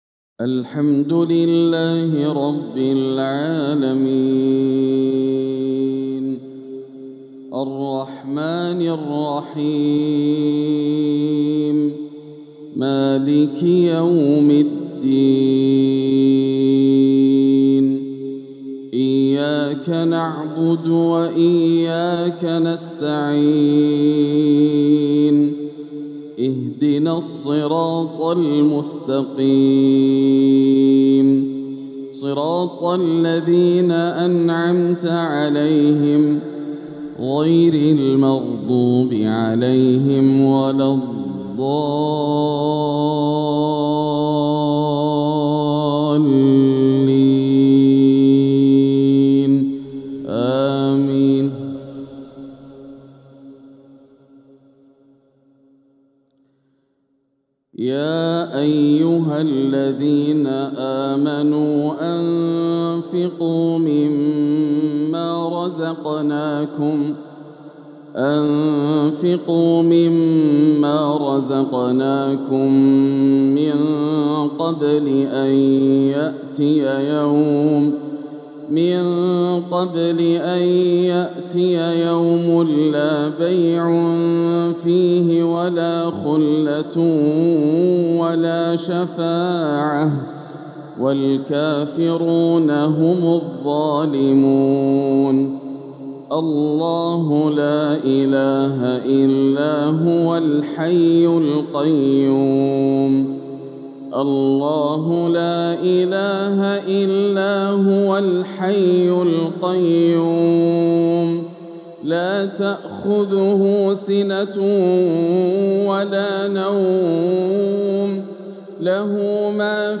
العقد الآسر لتلاوات الشيخ ياسر الدوسري تلاوات شهر ربيع الآخر عام ١٤٤٦ هـ من الحرم المكي > سلسلة العقد الآسر من تلاوات الشيخ ياسر > الإصدارات الشهرية لتلاوات الحرم المكي 🕋 ( مميز ) > المزيد - تلاوات الحرمين